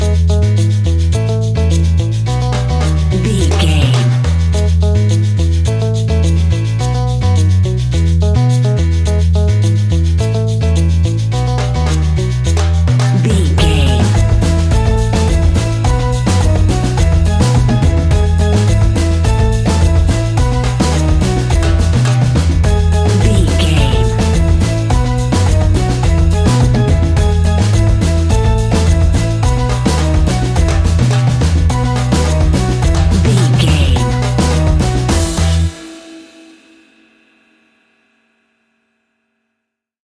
Ionian/Major
steelpan
drums
percussion
bass
brass
guitar